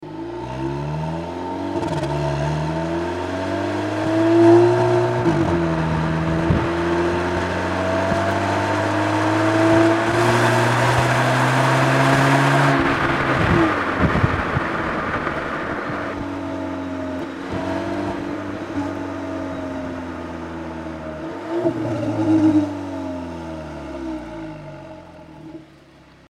Verpasse deinem Polo GTI den optimalen Sound mit der Abgasanlage von APR.
PoloGTI_OhneOPF_kofferraum.mp3